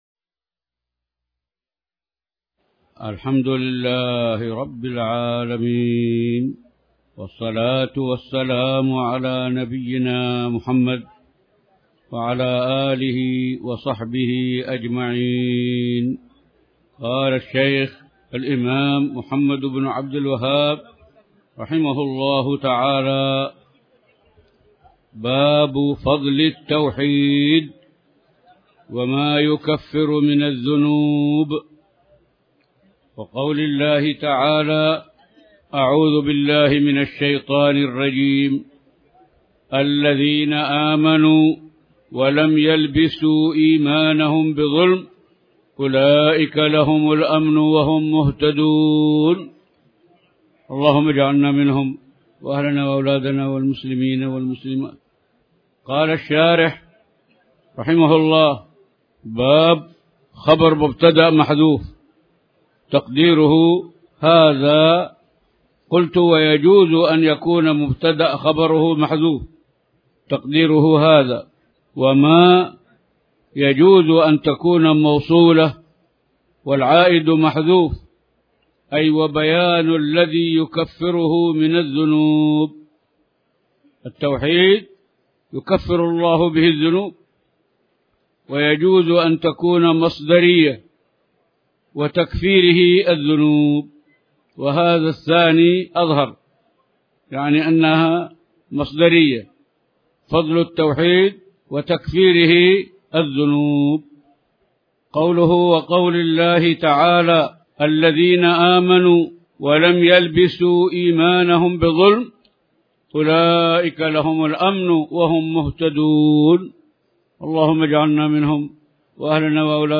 تاريخ النشر ٢ ذو الحجة ١٤٣٩ هـ المكان: المسجد الحرام الشيخ